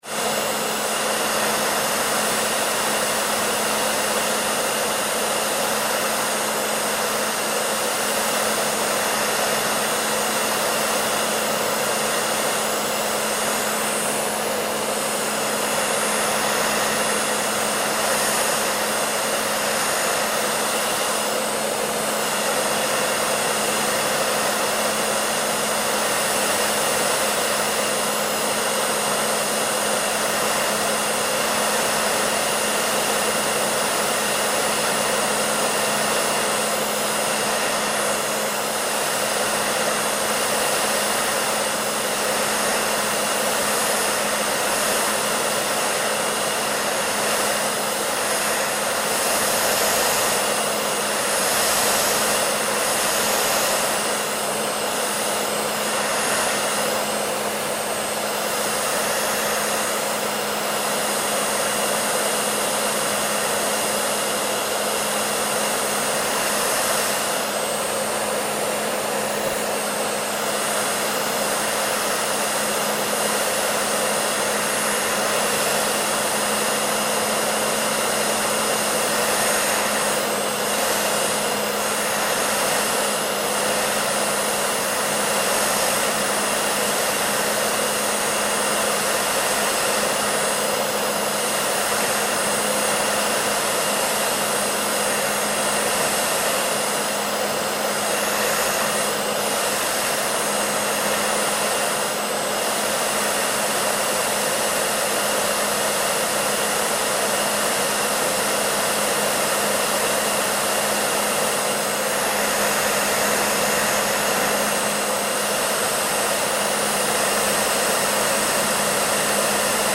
دانلود صدای سشوار از ساعد نیوز با لینک مستقیم و کیفیت بالا
جلوه های صوتی